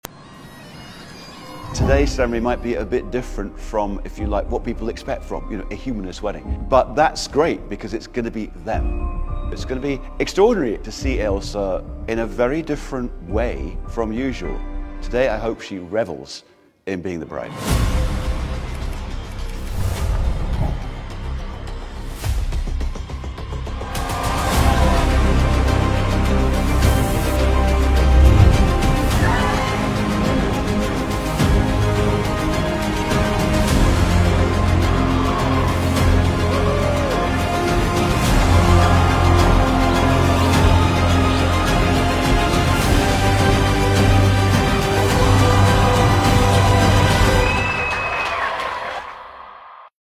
نام خواننده : بیکلام